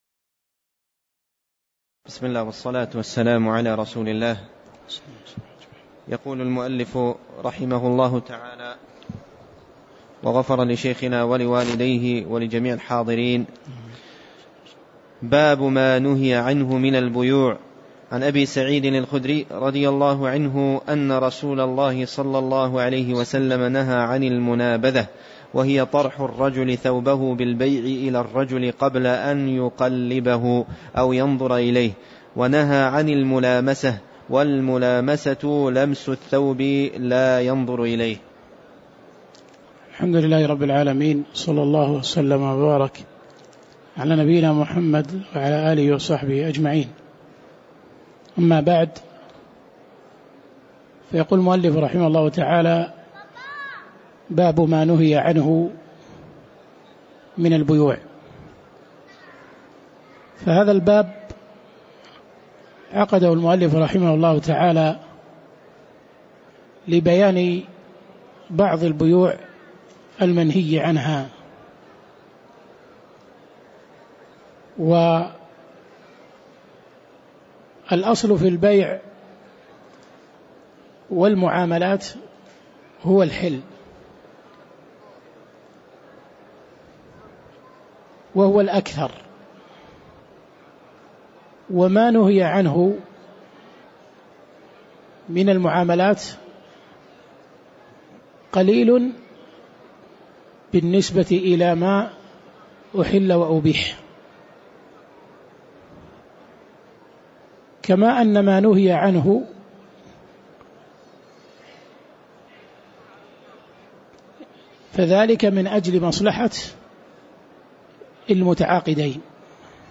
تاريخ النشر ١٢ رجب ١٤٣٨ هـ المكان: المسجد النبوي الشيخ